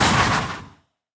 assets / minecraft / sounds / mob / breeze / hurt3.ogg
hurt3.ogg